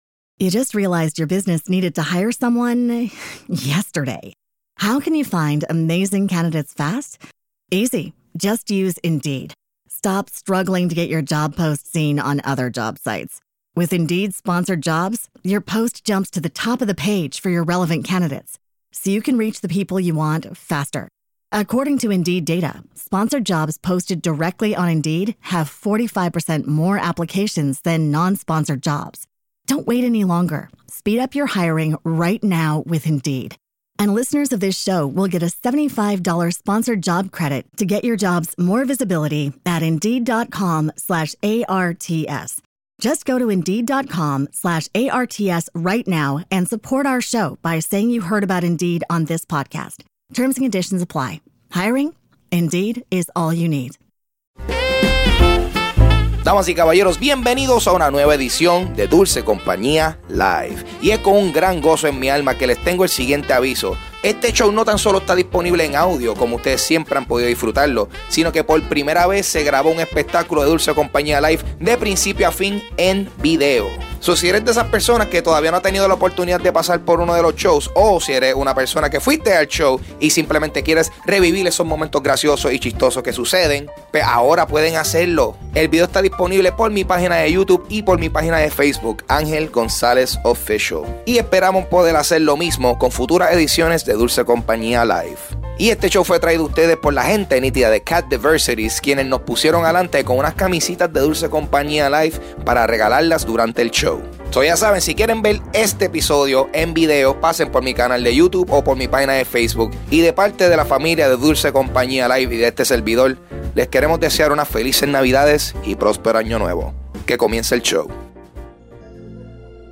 Grabado el 23 de diciembre de 2016 en Celebrate.